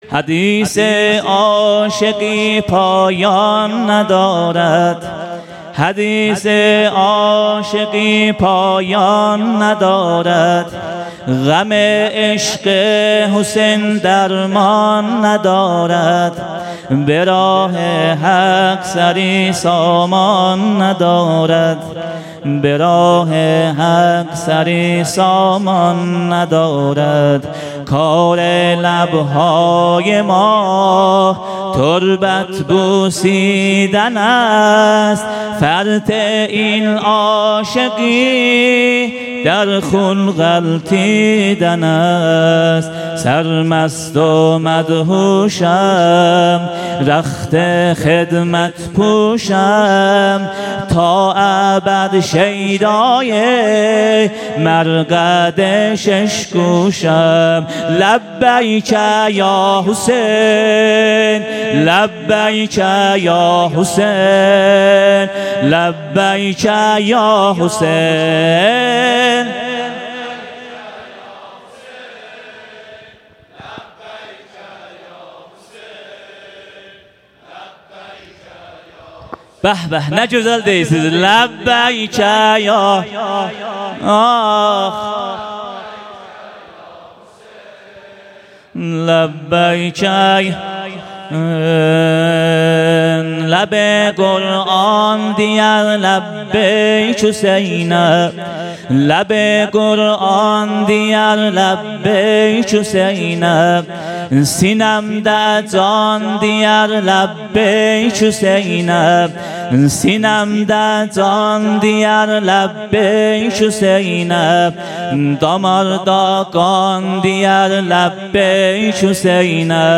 محرم ۹۵. شب هفتم ( بخش دوم سینه زنی)